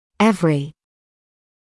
[‘evrɪ][‘эври]каждый; любой; всяческий